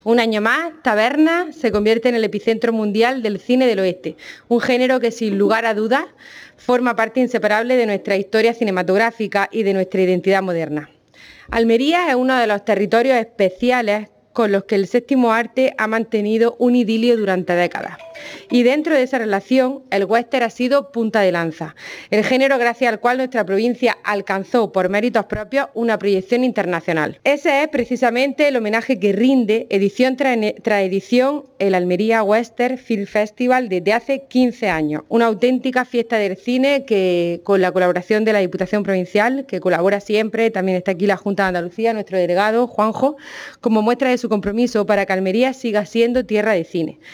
El Patio del Mandarino, sede de Cultura de la Diputación de Almería, ha sido el lugar elegido para dar a conocer la programación al completo.